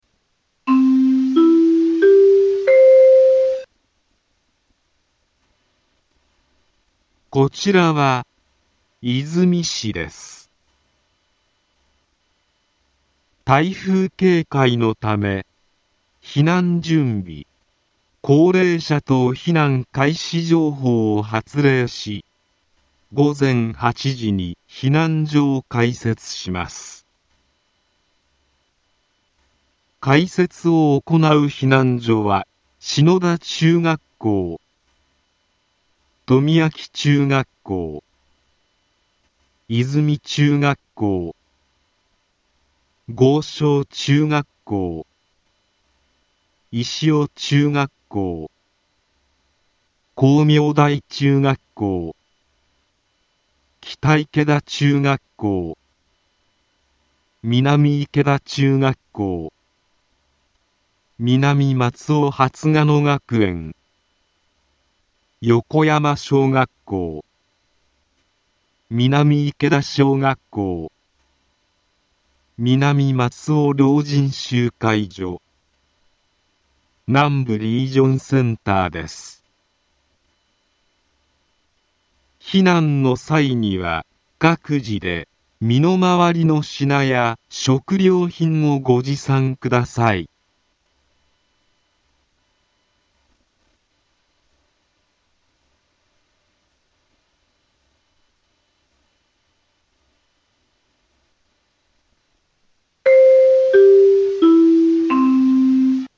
Back Home 災害情報 音声放送 再生 災害情報 カテゴリ：通常放送 住所：大阪府和泉市府中町２丁目７−５ インフォメーション： こちらは 和泉市です。台風警戒のため【警戒レベル３】避難準備・高齢者等避難開始情報を発令し、午前8時に避難所を開設します。